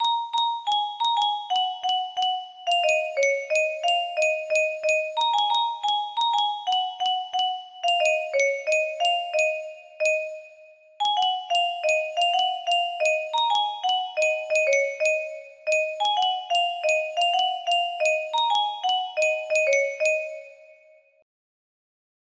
Medieval ballad in '800s style